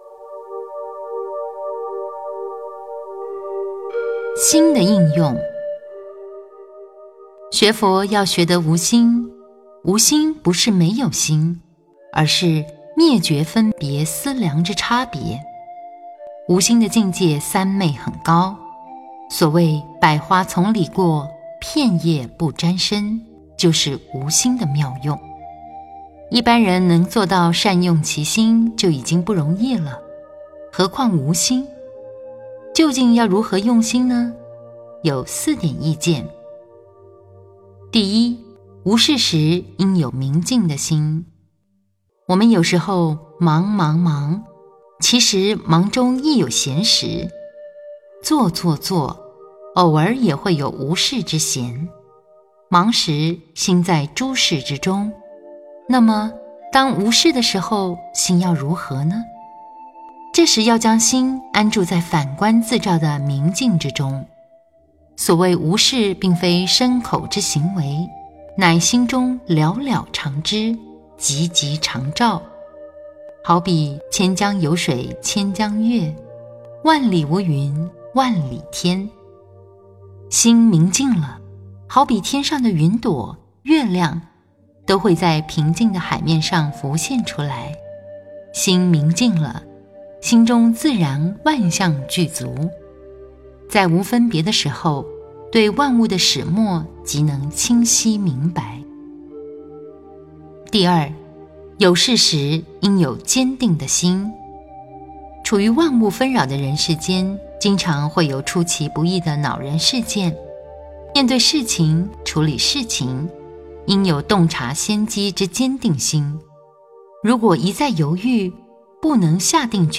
标签: 佛音冥想佛教音乐